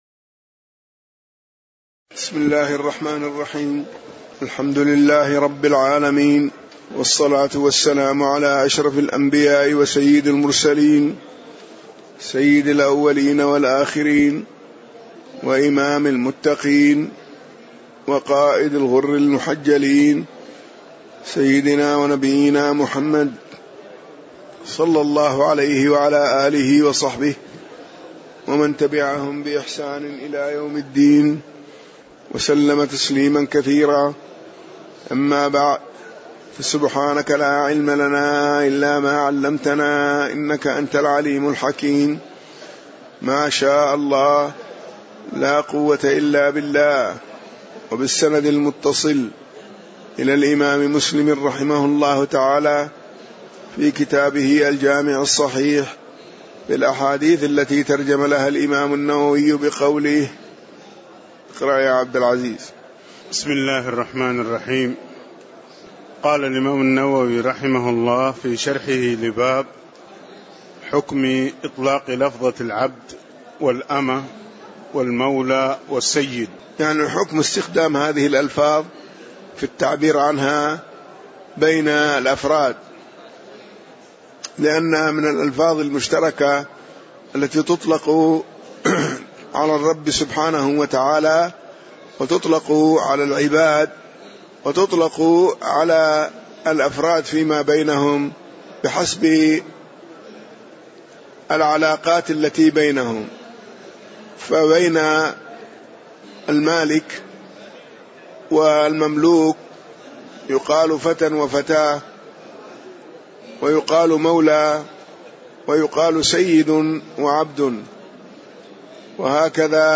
تاريخ النشر ٣ ربيع الثاني ١٤٣٧ هـ المكان: المسجد النبوي الشيخ